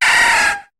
Cri de Phanpy dans Pokémon HOME.